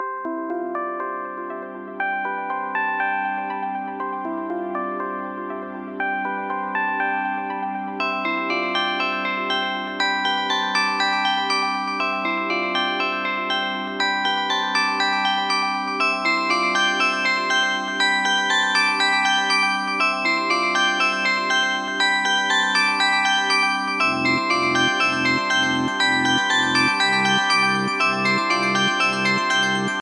Categoria Allarmi